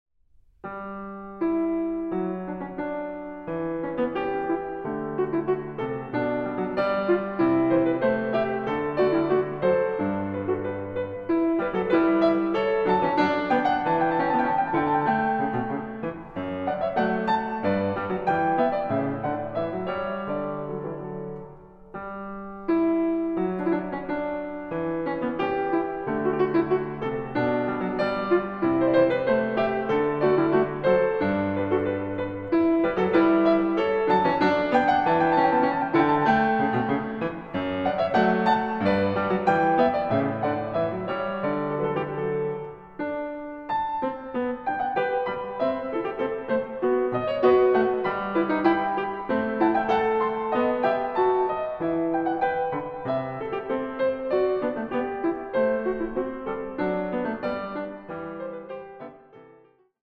a 1 Clav. alla breve